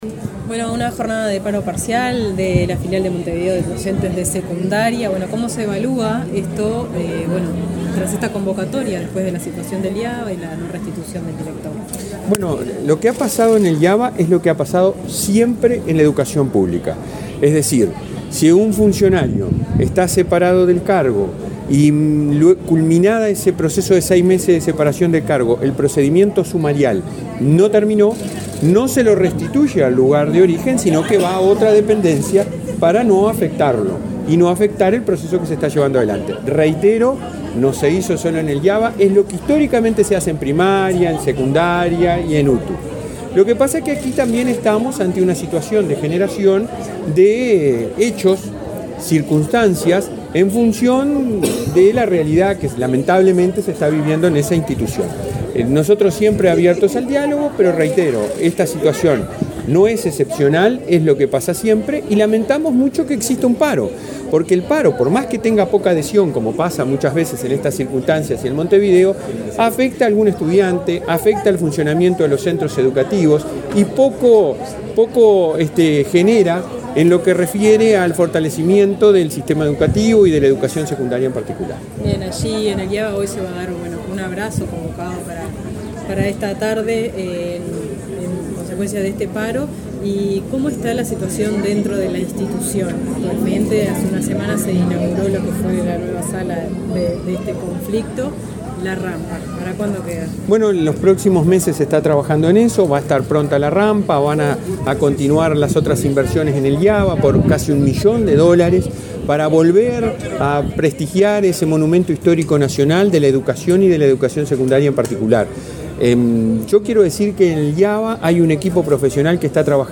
Declaraciones del presidente de ANEP, Robert Silva
Declaraciones del presidente de ANEP, Robert Silva 11/10/2023 Compartir Facebook X Copiar enlace WhatsApp LinkedIn La directora general de Educación Inicial y Primaria, Olga de las Heras, y el presidente de la Administración Nacional de Educación Pública (ANEP), Robert Silva, realizaron el lanzamiento de las inscripciones 2024 para ese ciclo educativo. Luego Silva dialogó con la prensa.